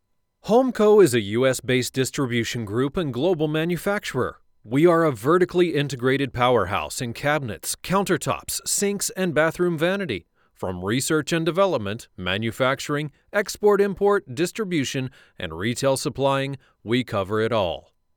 English (American)
Deep, Natural, Friendly, Warm, Corporate
Explainer